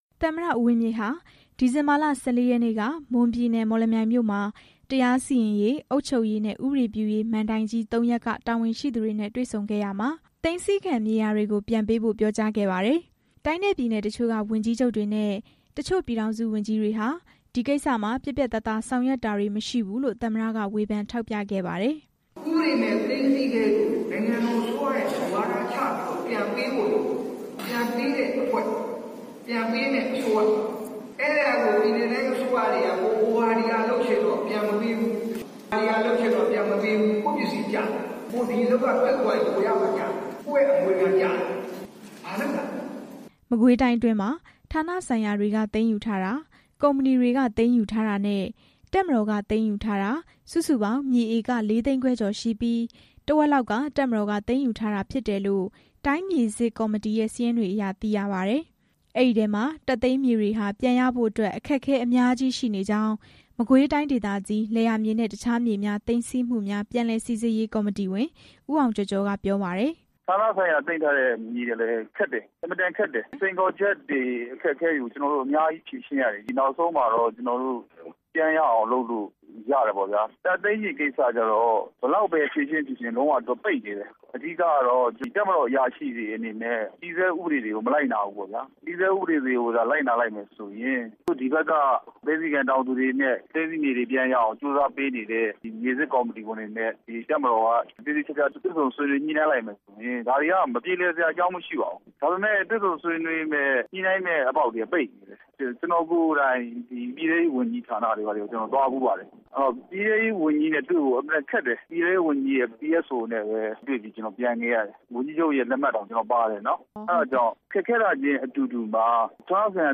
သမ္မတ ဦးဝင်းမြင့်ရဲ့ ခရီးစဉ်အကြောင်း တင်ပြချက်